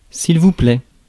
Ääntäminen
Ääntäminen France: IPA: [plɛ] Haettu sana löytyi näillä lähdekielillä: ranska Käännöksiä ei löytynyt valitulle kohdekielelle.